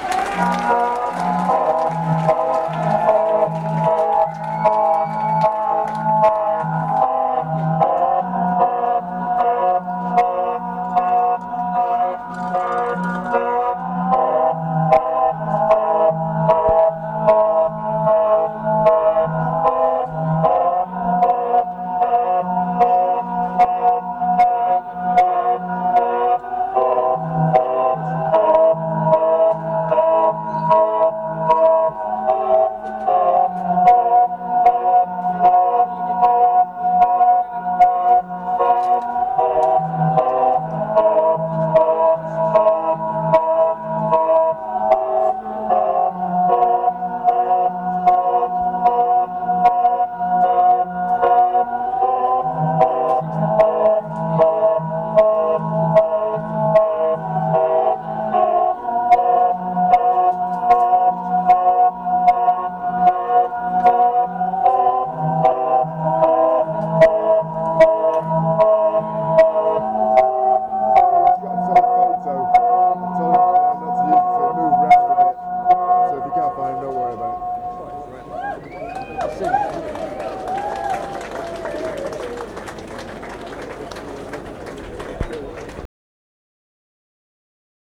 venue Camber Sands